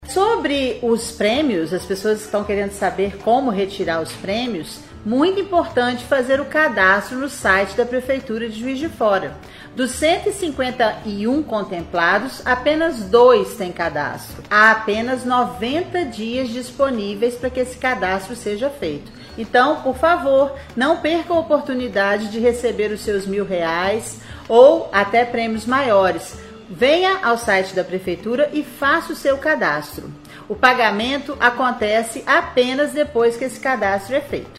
A secretária de Fazenda, Fernanda Finotti explica como deve ser retirado o prêmio.